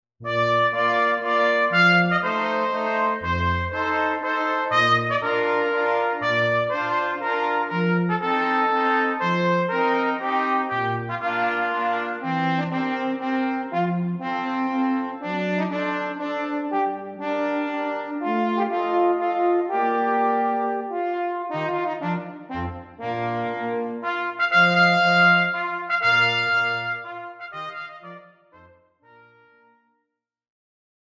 Flexible Ensemble (Woodwind, Brass and String instruments)